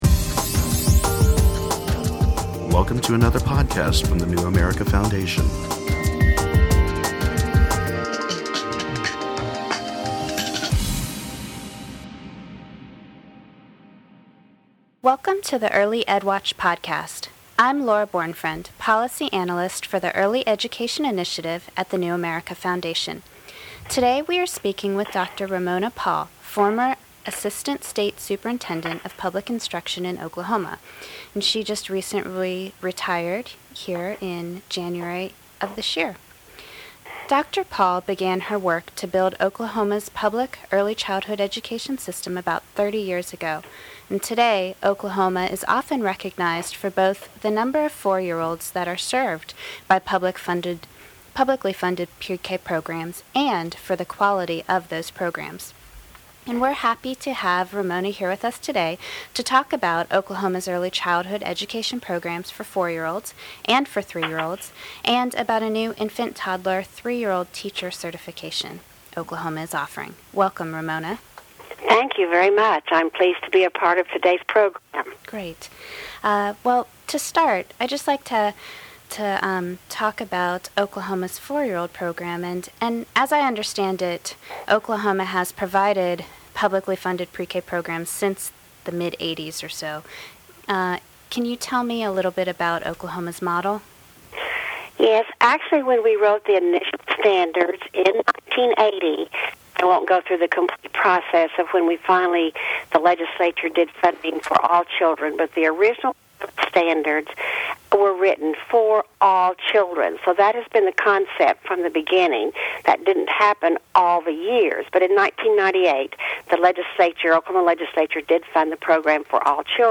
She describes the state’s model of pre-k for 4-year-olds, new publicly funded programs to serve 3-year-olds, and efforts to improve the quality of care and education in programs that serve infants and toddlers. Early Ed Watch podcast – February 21, 2011 Podcast: Early Education in Oklahoma With our guest Ramona Paul, former Assistant State Superintendent of Public Instruction of the Oklahoma Department of Education.